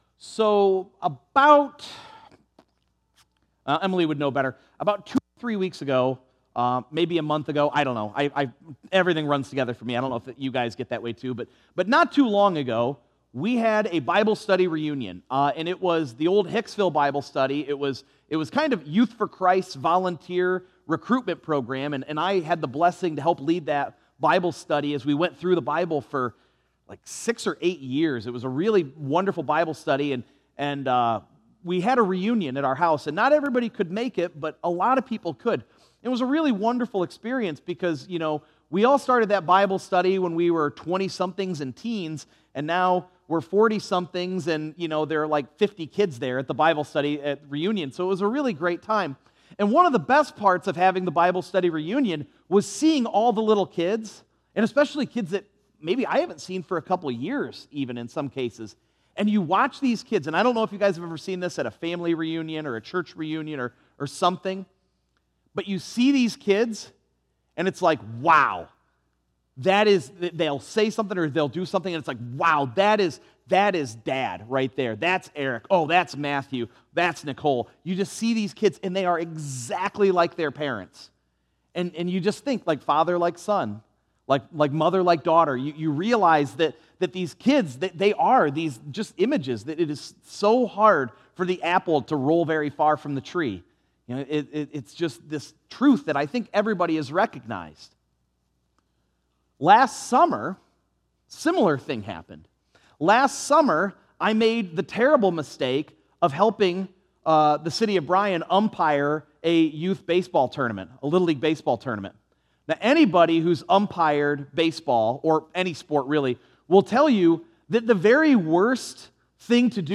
7_28_24_sunday_sermon.mp3